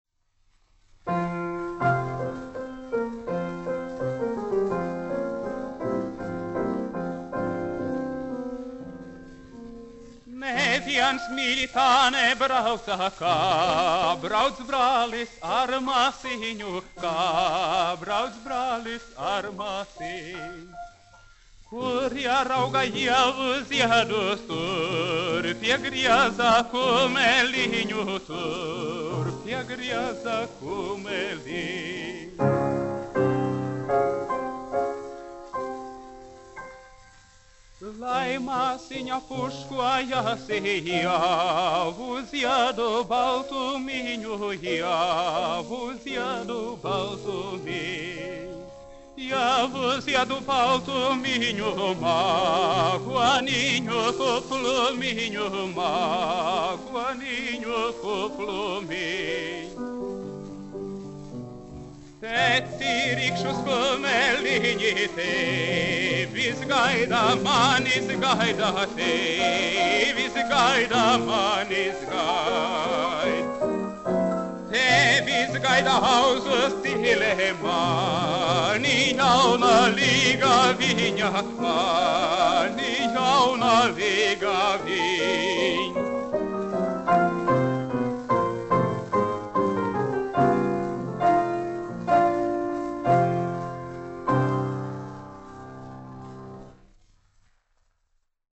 1 skpl. : analogs, 78 apgr/min, mono ; 25 cm
Dziesmas (augsta balss) ar klavierēm
Skaņuplate